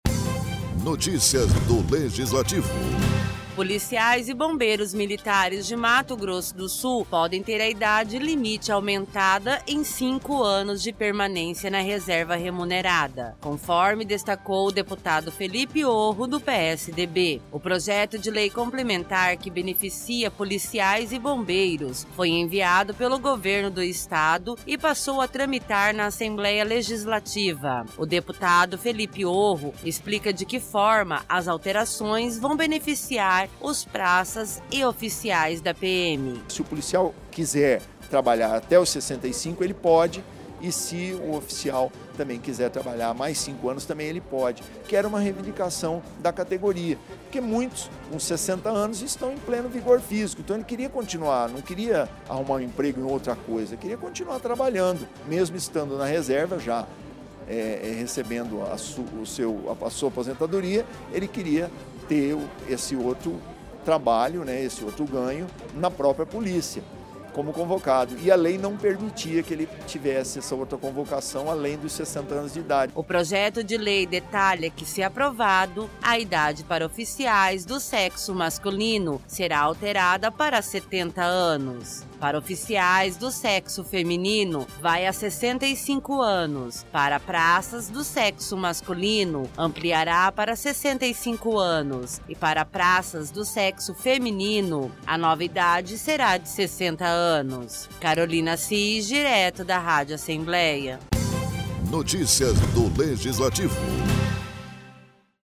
O deputado estadual Felipe Orro, do PSDB usou a tribuna para defender alterações no Estatuto da PM, que vai beneficiar policiais e bombeiros militares do Estado. Trata-se do Projeto de Lei Complementar 16/2019, que acrescenta dispositivos à Lei Complementar 53/1990, que dispõe sobre o Estatuto dos Policiais Militares de Mato Grosso do Sul, visando aumentar em cinco anos as idades limites de permanência na reserva remunerada.